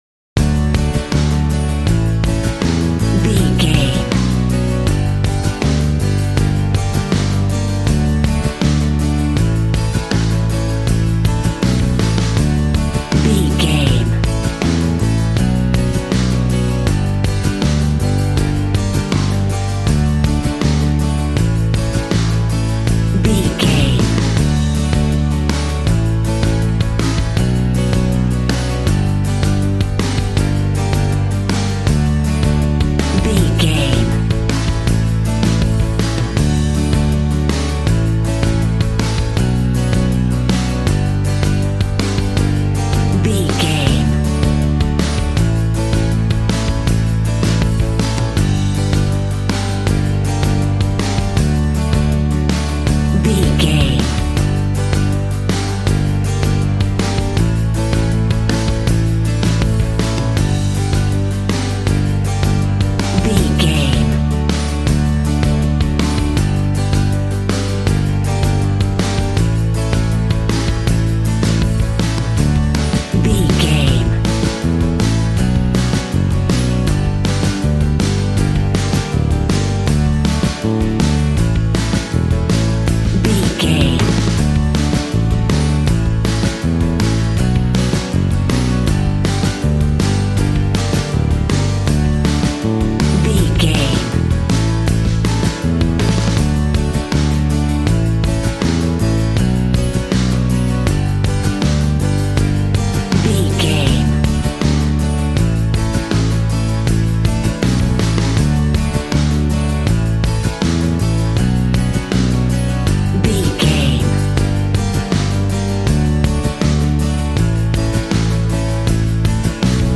Ionian/Major
D
Fast
fun
bouncy
positive
double bass
drums
acoustic guitar